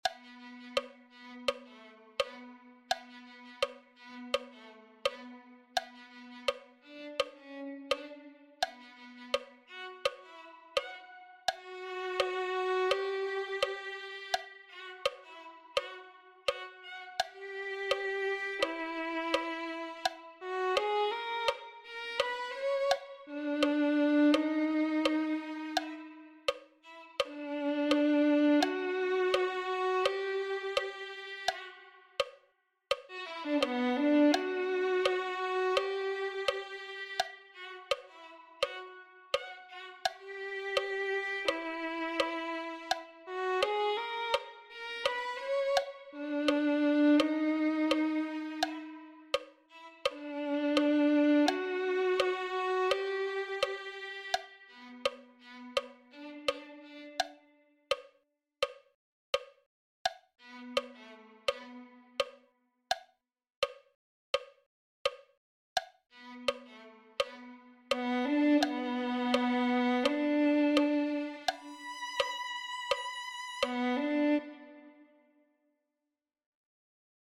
spirituál